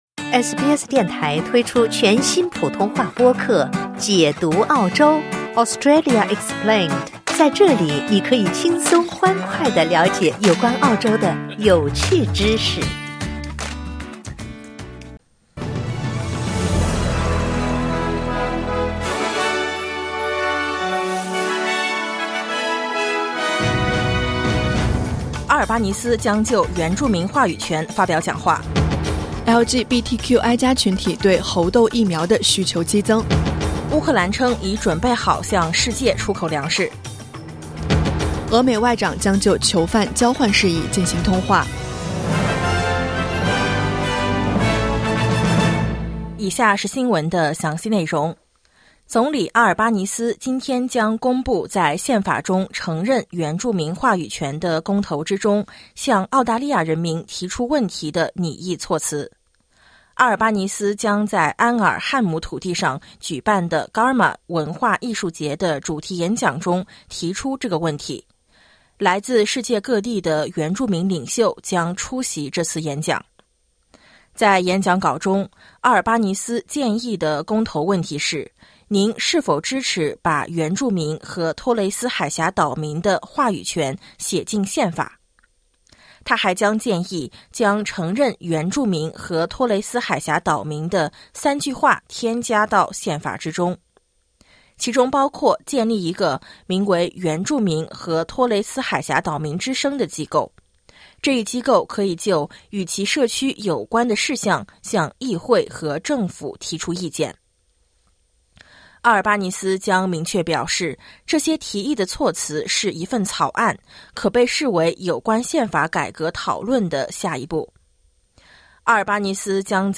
SBS早新聞（2022年7月30日）